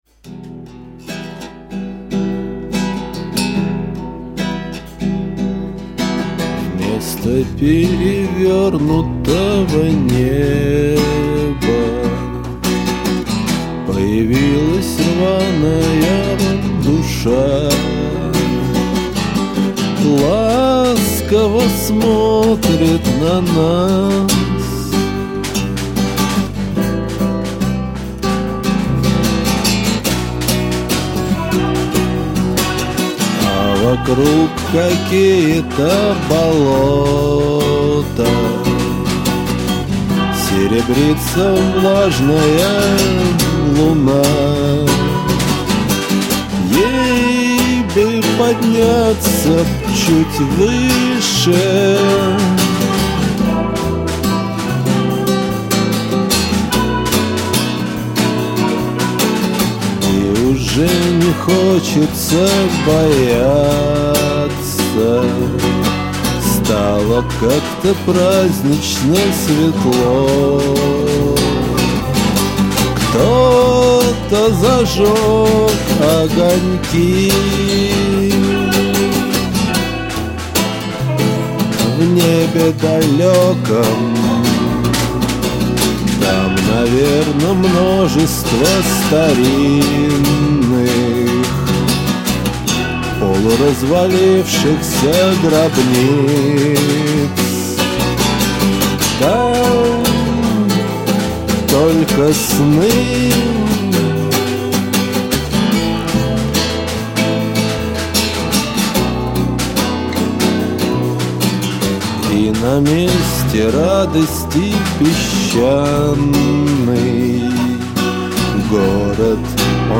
вокал.
клавиши, гитара.
бас.
перкуссия, ударные.